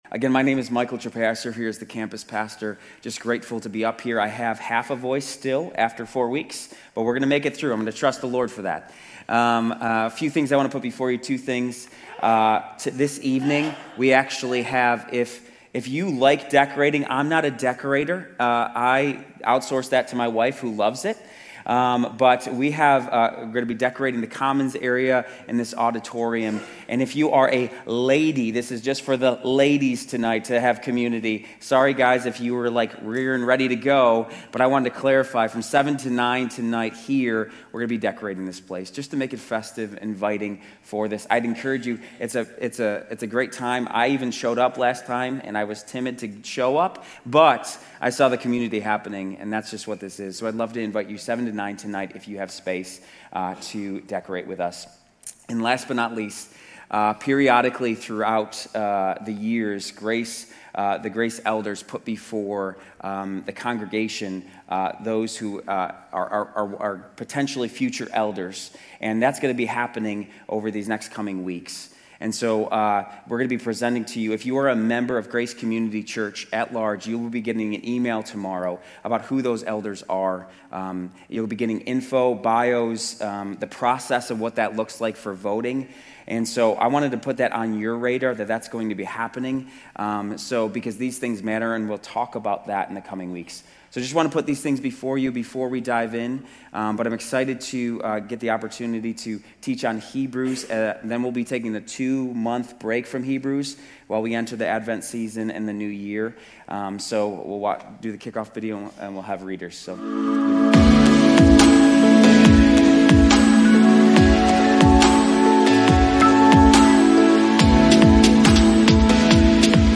Grace Community Church University Blvd Campus Sermons 11_23 University Blvd Campus Nov 24 2025 | 00:36:36 1x 00:00 / 00:36:36 Subscribe Share RSS Feed Share Link Embed